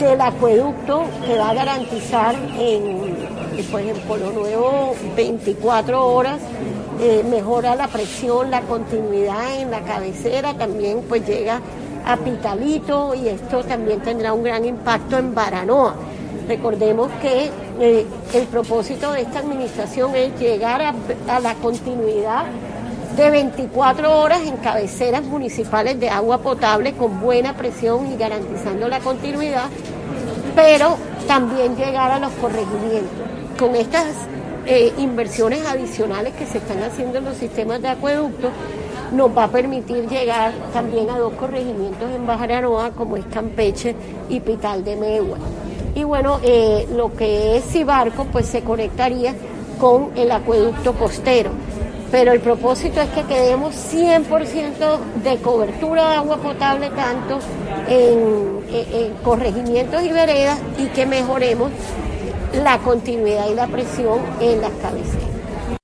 La mandataria departamental realizó un recorrido este martes en el lugar donde se desarrollan las obras, y expresó que se estará garantizando el servicio las 24 horas en las cabeceras municipales.
VOZ-ELSA-ACUEDUCTO-BARANOA.mp3